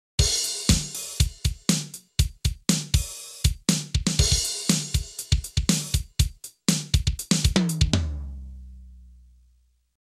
The input audio signal generated by a MIDI sound module is a performance of this score represented by a standard MIDI file (SMF). The tempo is 120 M.M.